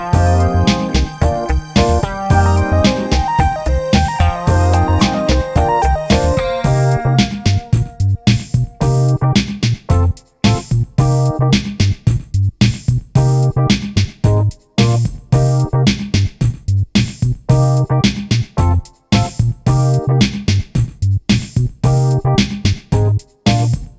no sax solo Pop (1980s) 4:49 Buy £1.50